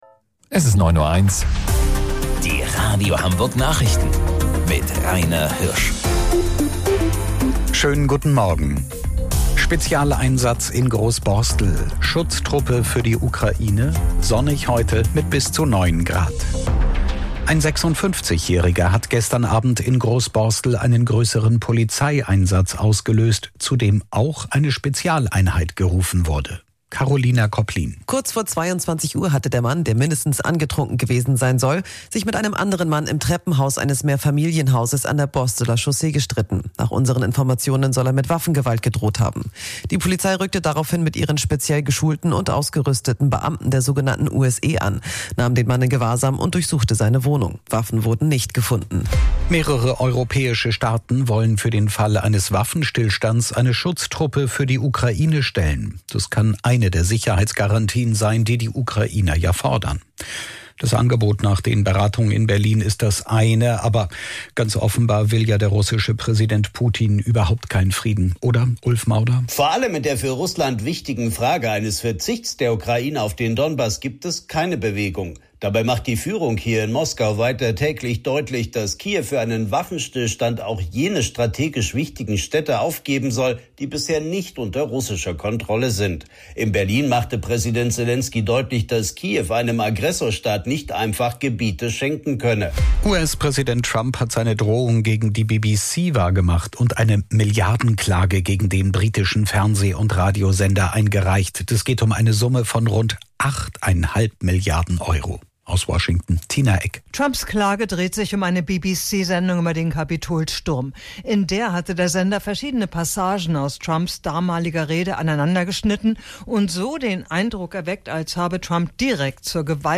Radio Hamburg Nachrichten vom 16.12.2025 um 09 Uhr